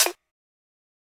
BattleCatPercHit.wav